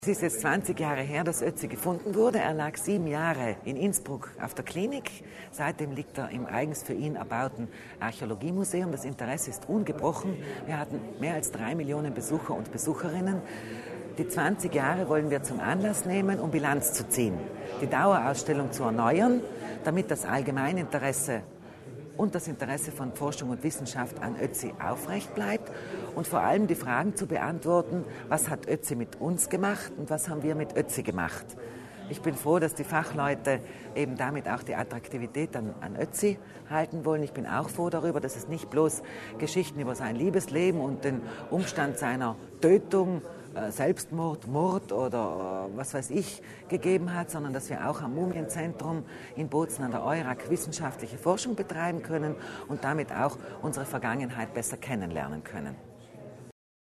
Landesrätin Kasslatter Mur zum wichtigen Jubiläum